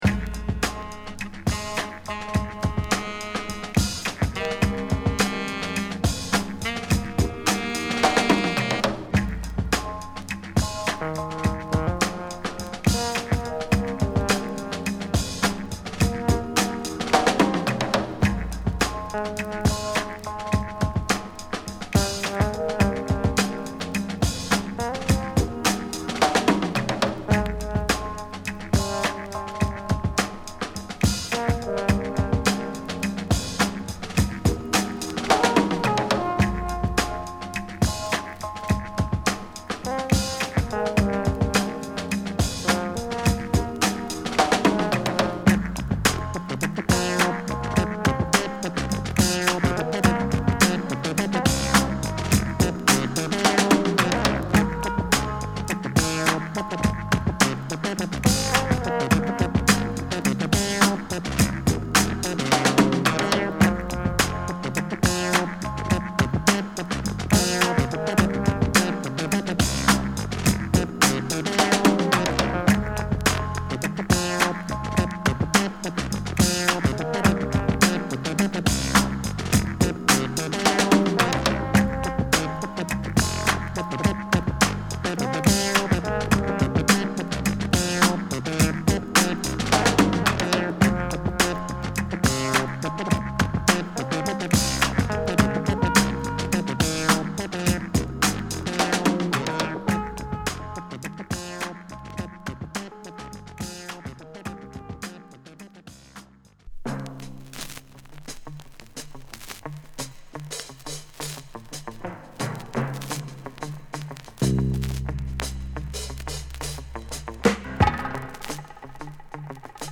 ハードボイルドなサントラらしいシリアスなサウンドが詰まった名作！
＊B1頭にノイズ有り。